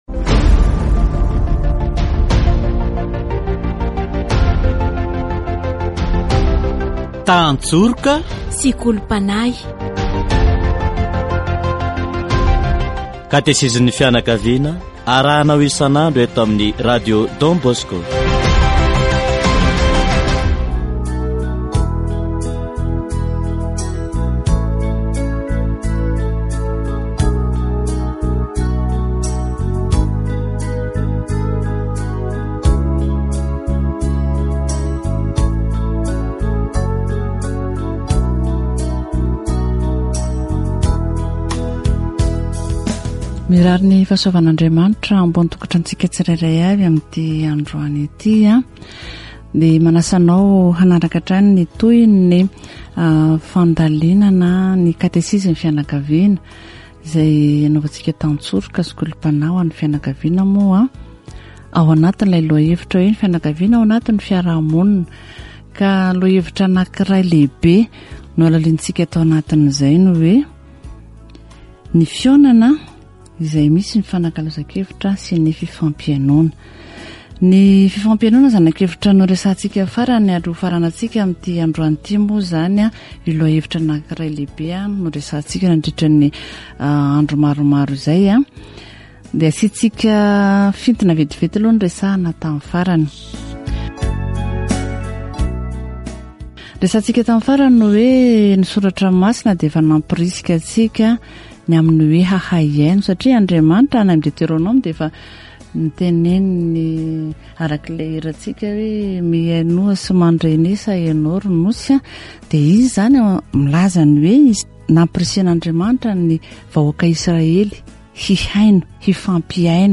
Catégorie : Approfondissement de la foi
Catéchèse sur l'échange d'idées